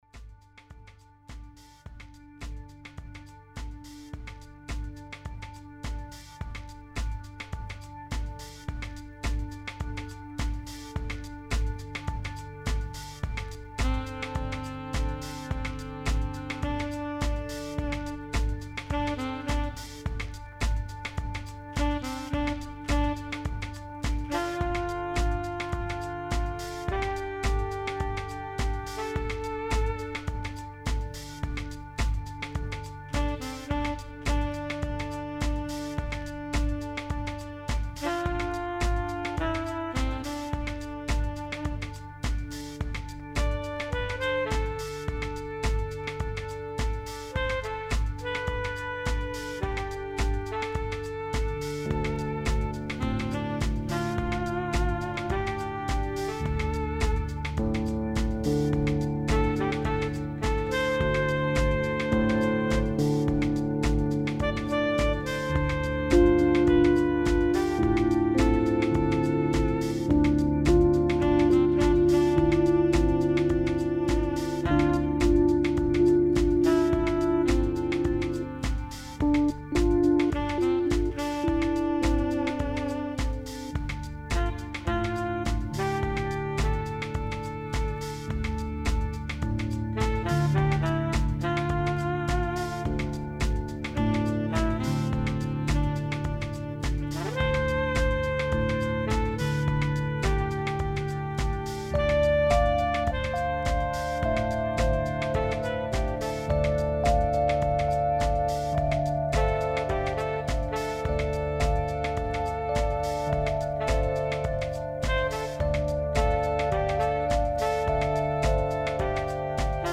Blues, Folk, Singer Songwriter, Deltablues